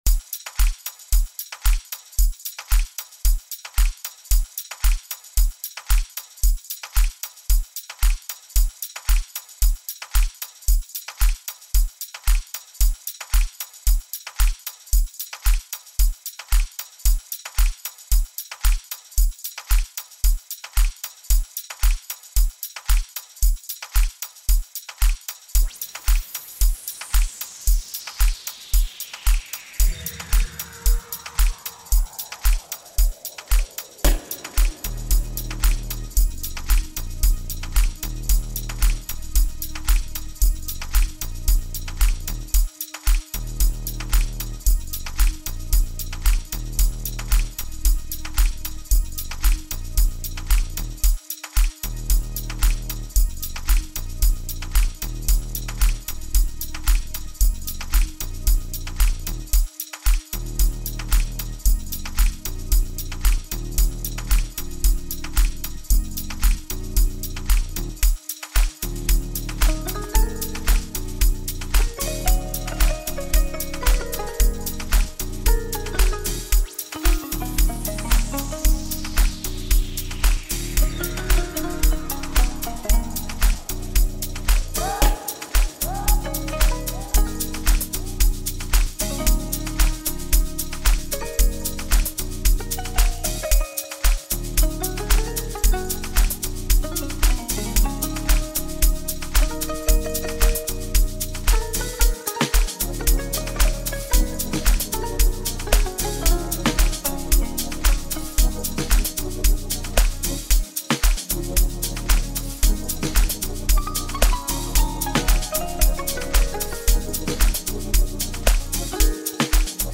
As a seasoned artist under the banner of Amapiano